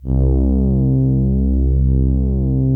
CN1 PAD 3.wav